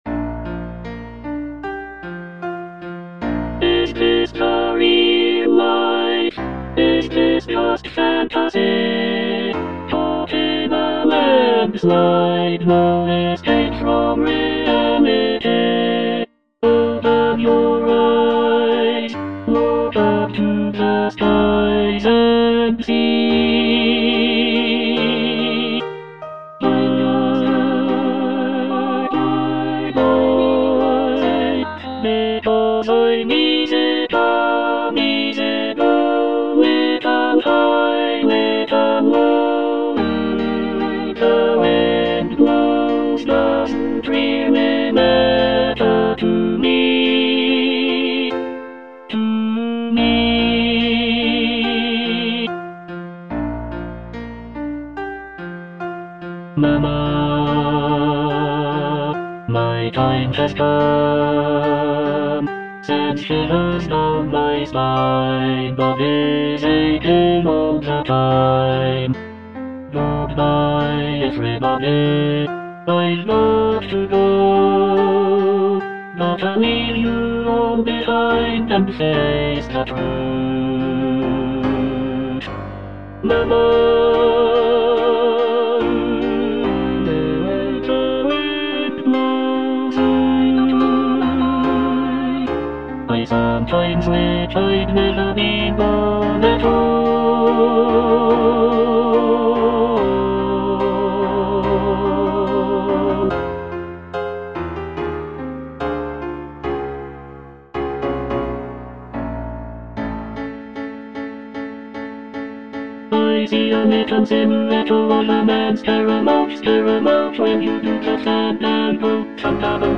Tenor I (Emphasised voice and other voices)